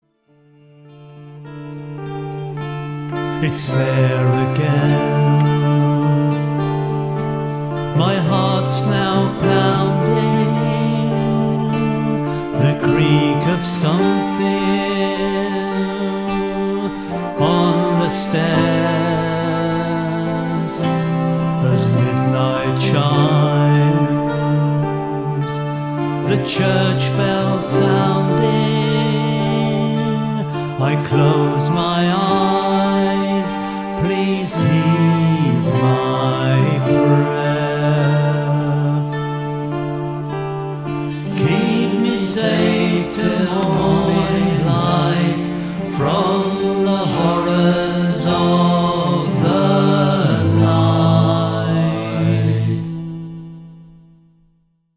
♫  Play sample with a bit of atmosphere